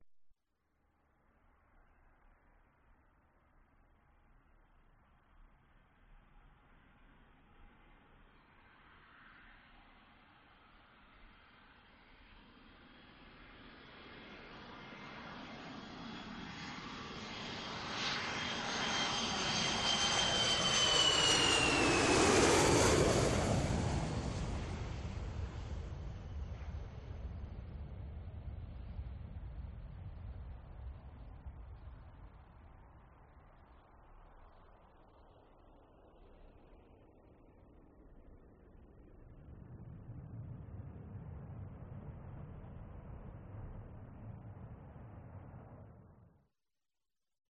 Звук приземления Боинг 737 движение слева направо